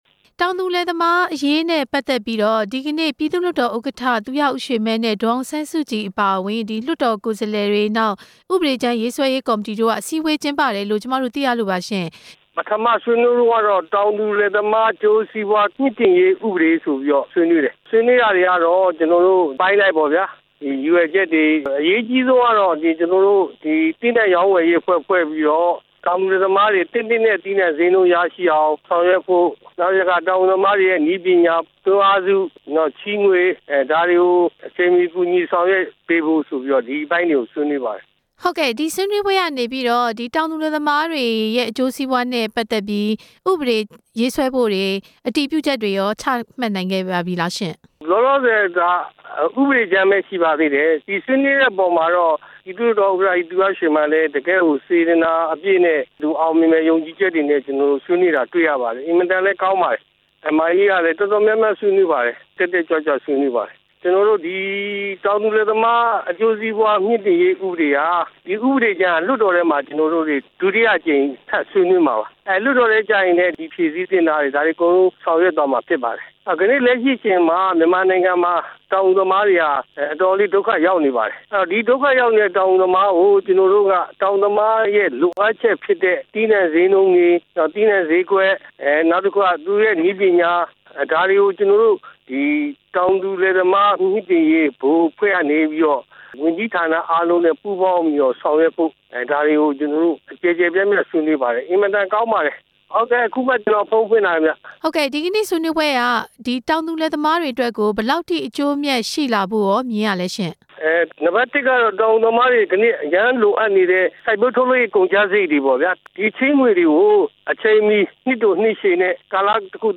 ဦးစိန်ဝင်းဟန်နဲ့ ဆက်သွယ်မေးမြန်းချက်